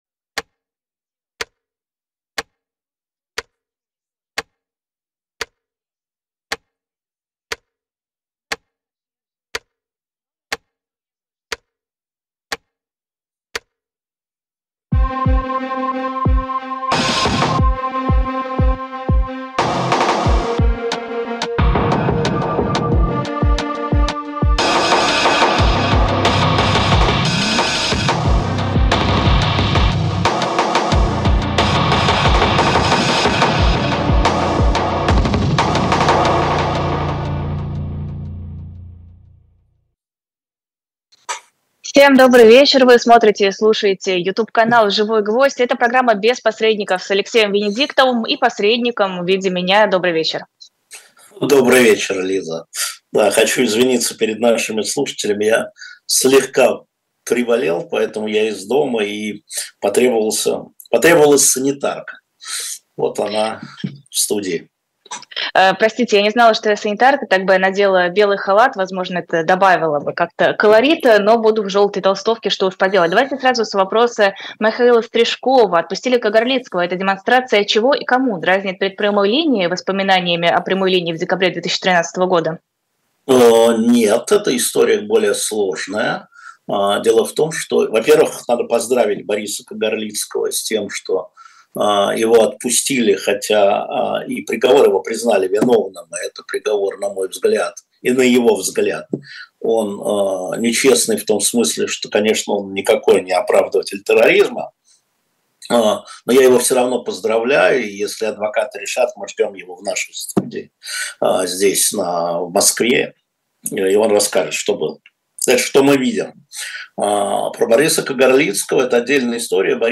Эфир Алексея Венедиктова